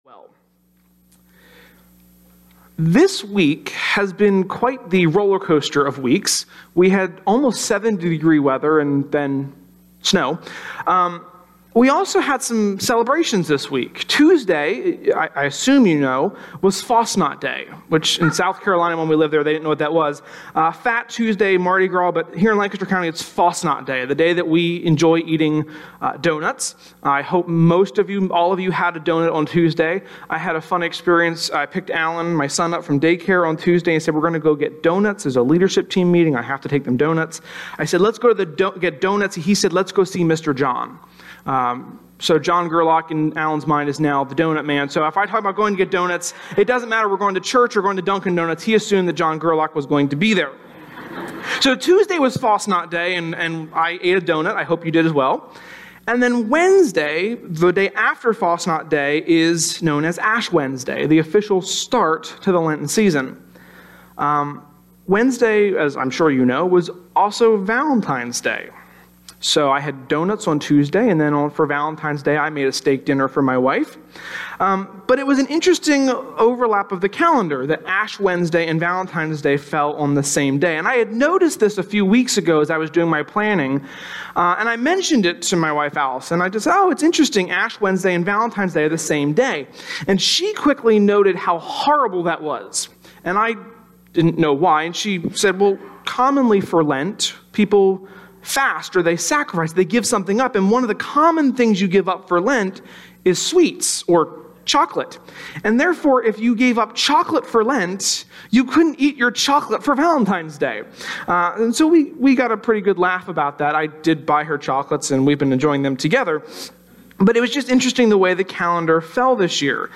sermon-2.18.18.mp3